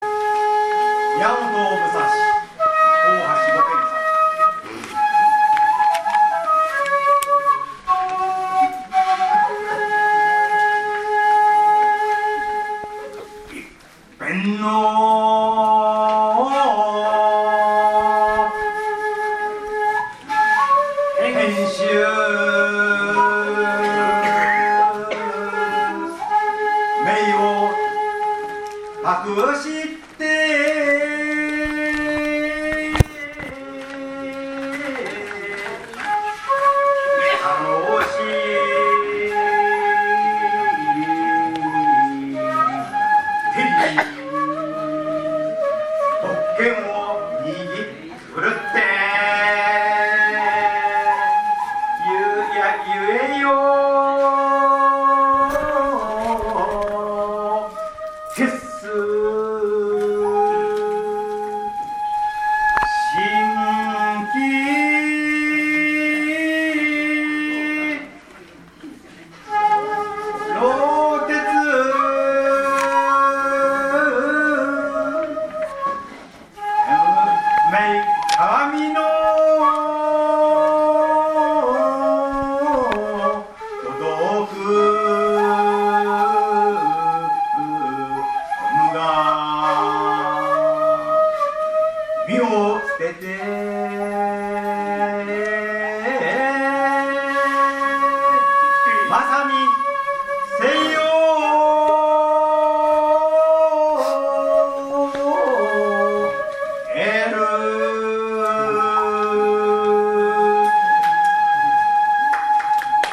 詩吟神風流 第９１回全国大会特集
平成２９年１１月１８日（土） 於 上野精養軒